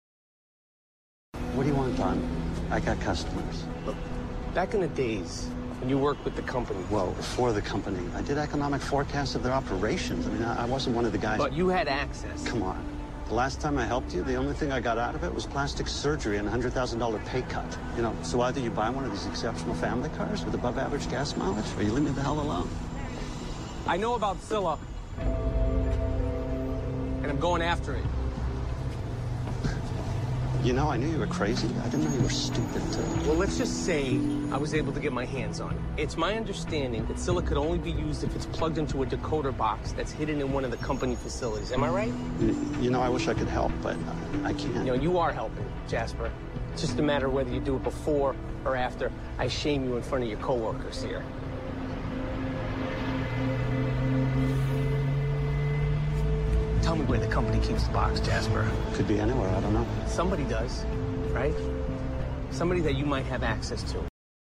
每期除了精彩的影视剧对白，还附有主题句型。